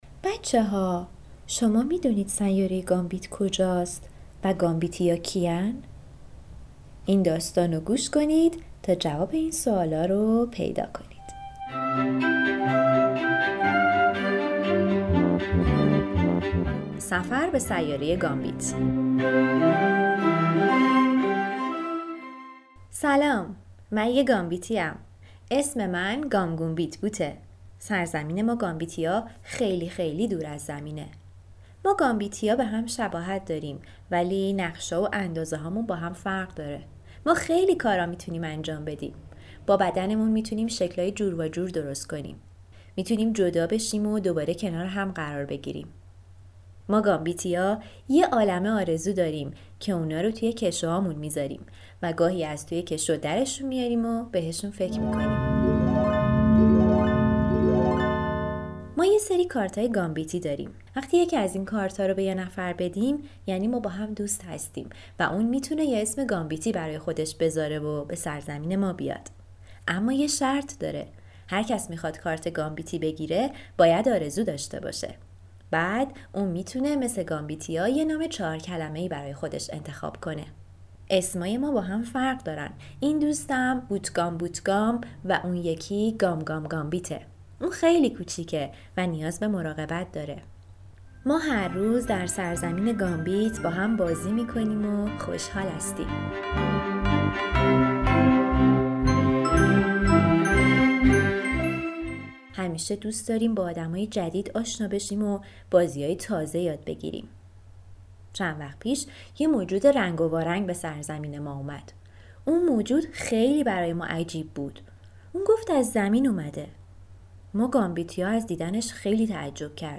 قصه گو